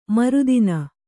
♪ maru dina